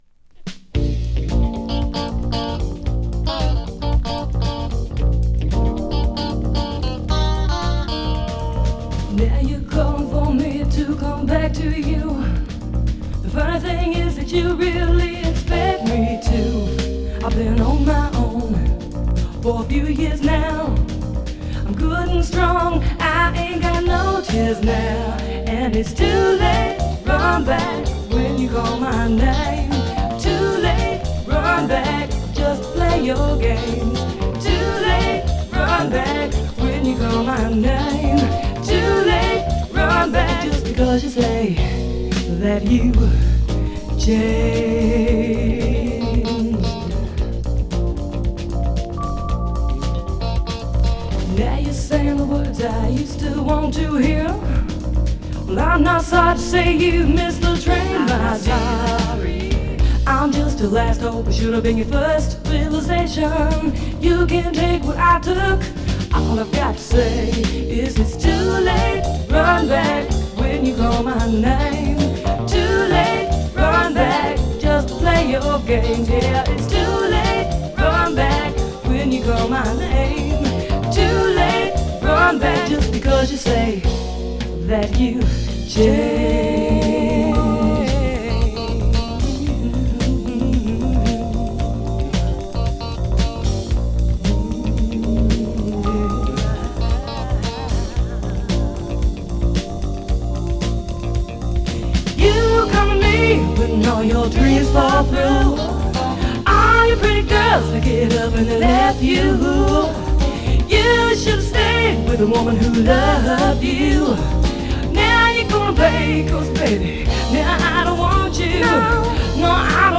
ピアノ弾きのSSWによるデビュー盤で内容はAOR〜ポップ・ロックという感じでしょうか。